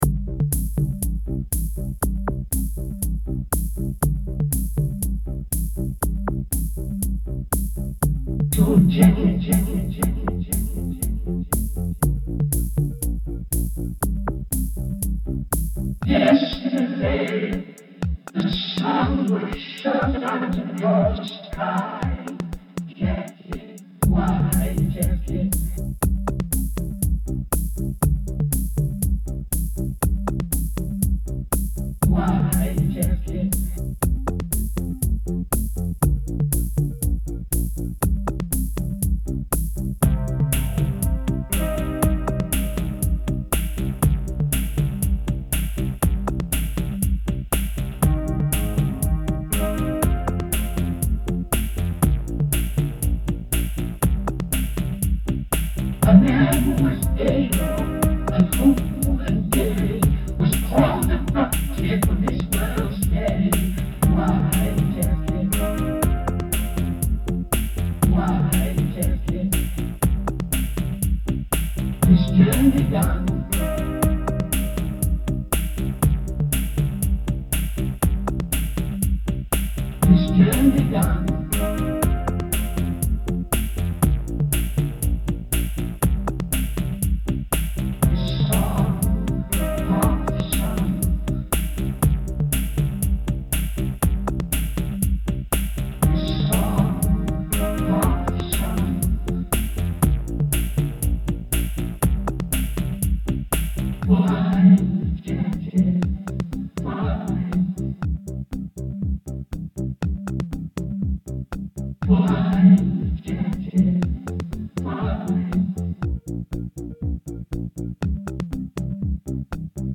This is more of a tribute to JFK and Jackie K. I have a record of JFK speaches and tributes and stuff so I sampled that through a microkorg vocoder in the key of some chords that are in the song. the guitary sound is a pre recorded sample from a yamaha DJX keyboard and half of the beat was made in ableton and the other half was from a rhythm on the yamaha.
PS sorry if the volume is too low.
I like the feel of this a lot... of course, I'm always in favor of simple electronic beats.
Spooky, It sounds like hes talking across time, like a ghost.
I also like the breakdown at 0:40 musically.